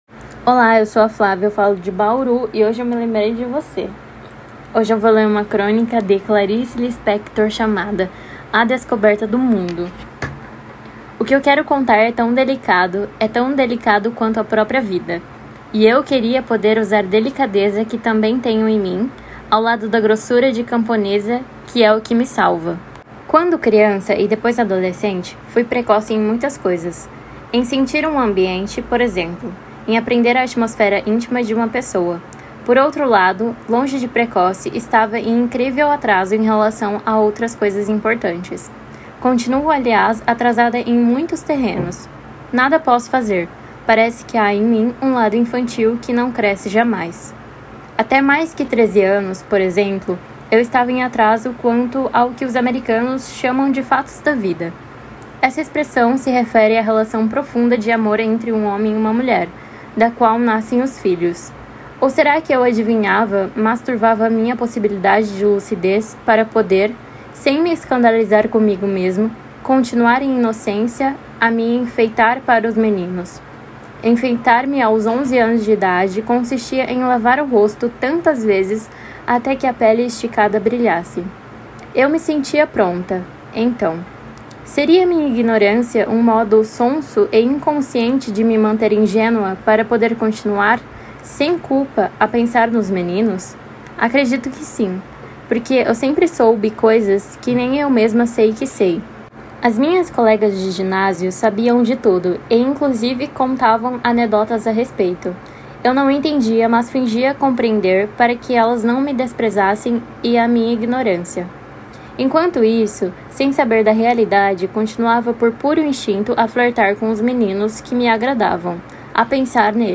Crônica Português